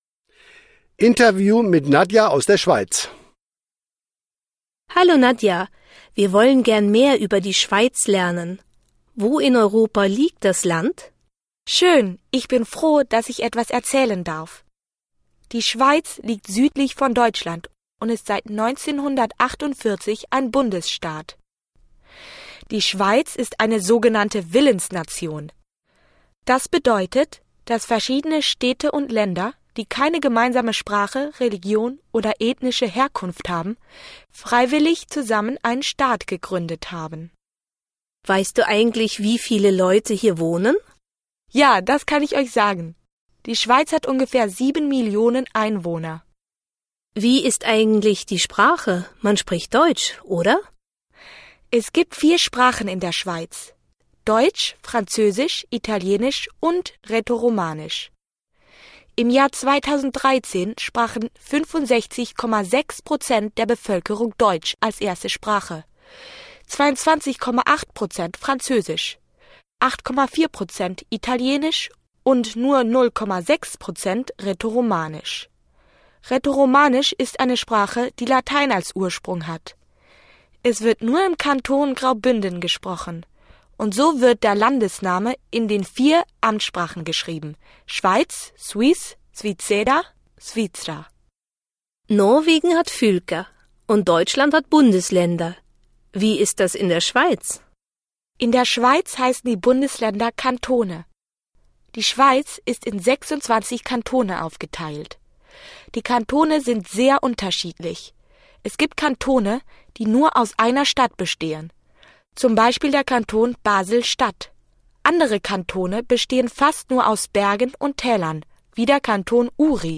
Die Schweiz – Interview